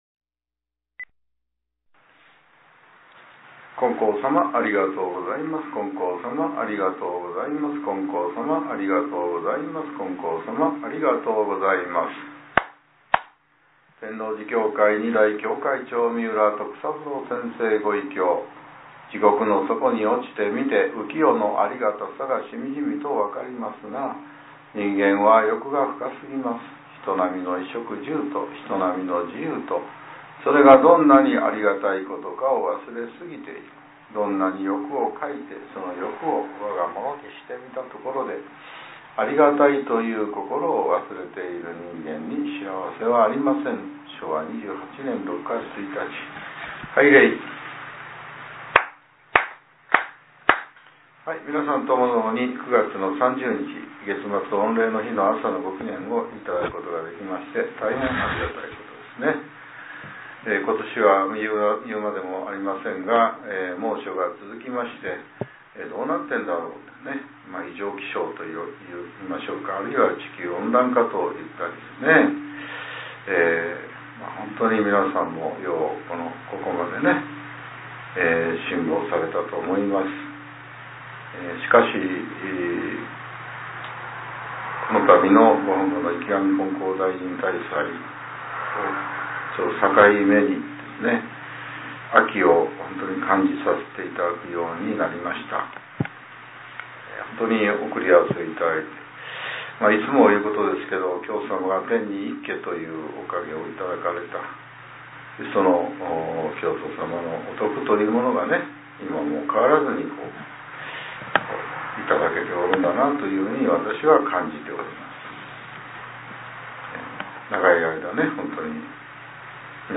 令和７年９月３０日（朝）のお話が、音声ブログとして更新させれています。